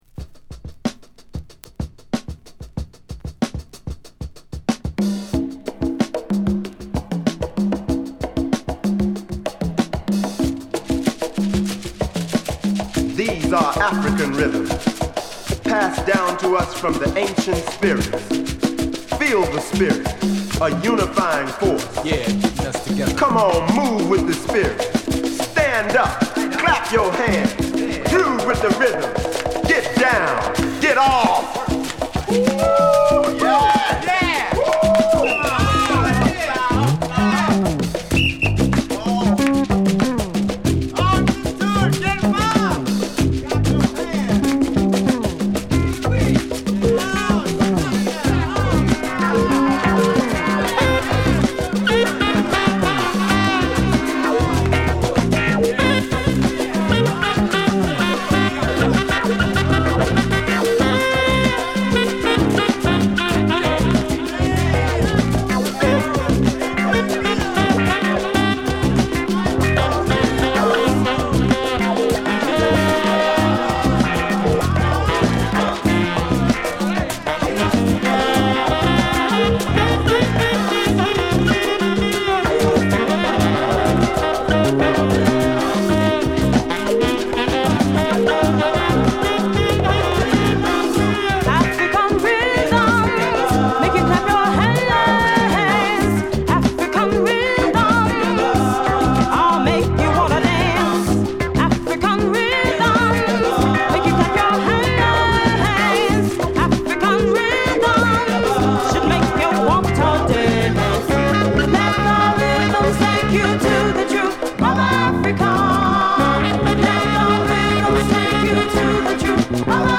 唯一無二のアフロ/ファンクを披露！